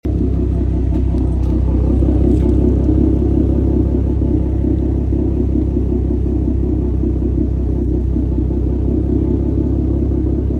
ISR with Motordyne Exhaust on sound effects free download
ISR with Motordyne Exhaust on my 370z